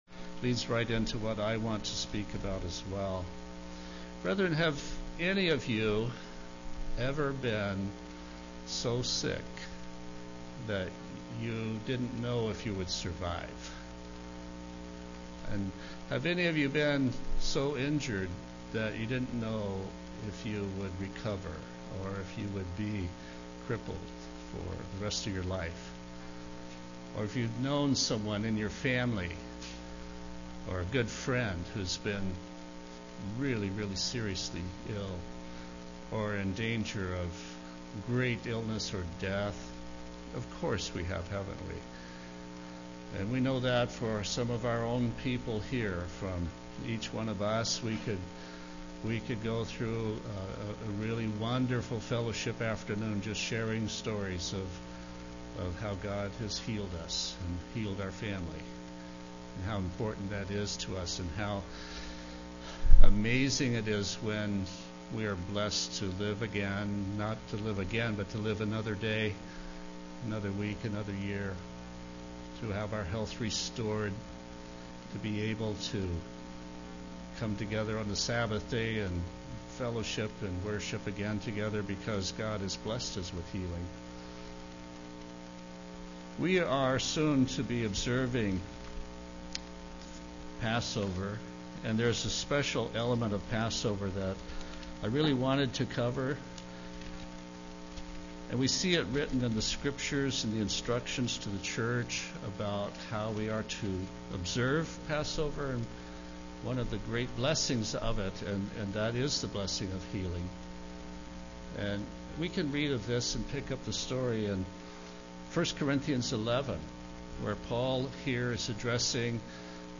Given in Olympia, WA Tacoma, WA
UCG Sermon Studying the bible?